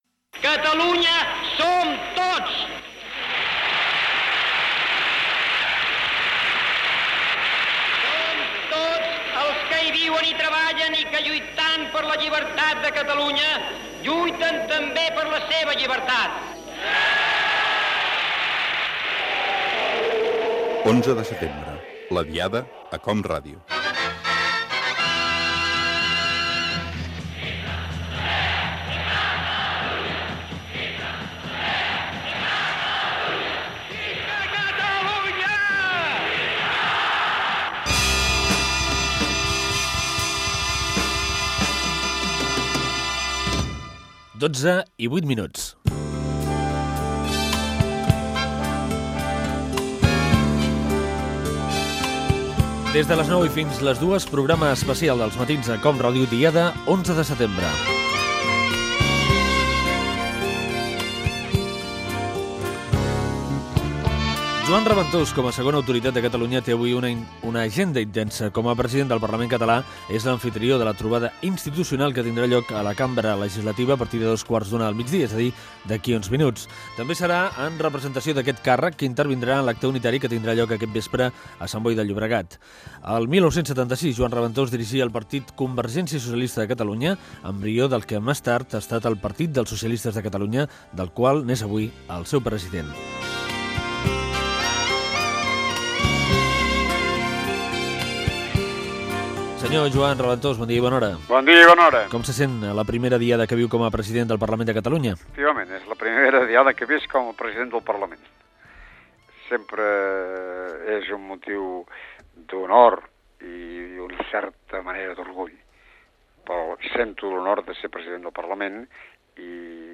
Indicatiu del programa de l'11 de setembre. Fragment de l'entrevista a Joan Raventós, president del Parlament, tot parlant de la seva primera Diada de l'11 de setembre amb aquell càrrec
Info-entreteniment